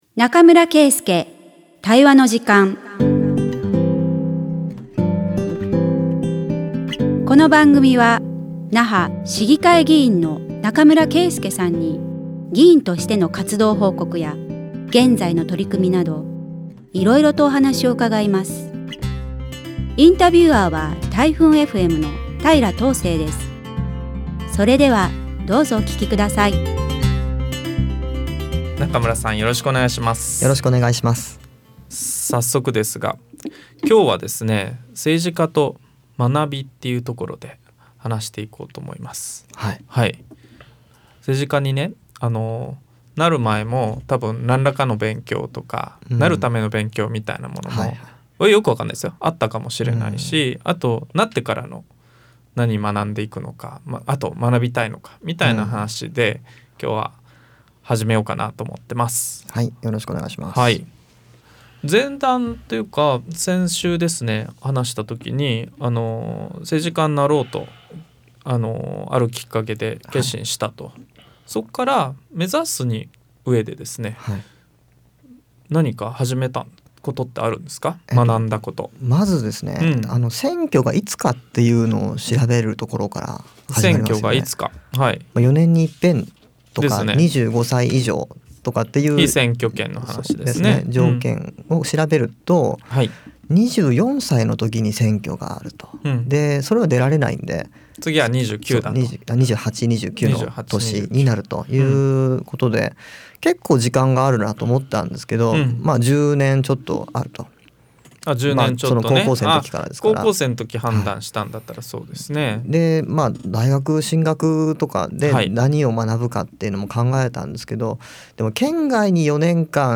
140307中村圭介対話の時間vol.02 那覇市議会議員中村圭介が議員活動や現在の取組みを語る20分